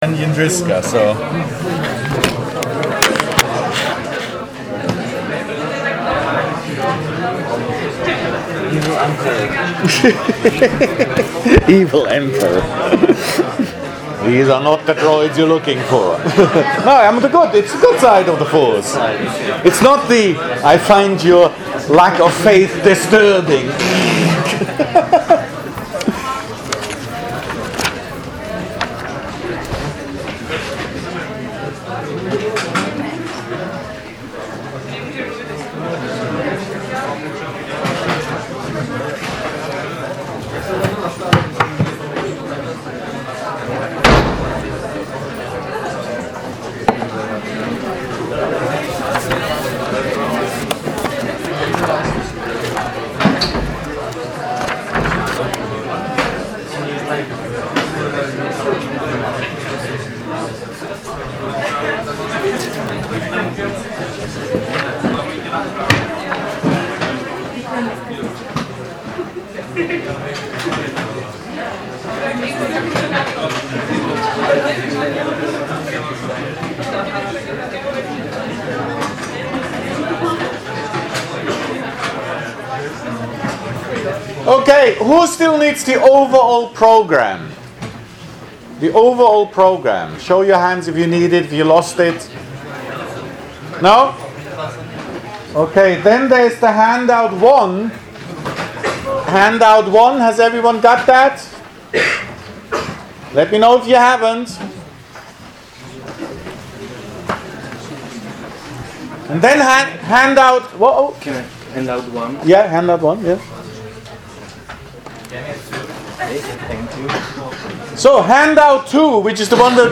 3._Discussion_2.MP3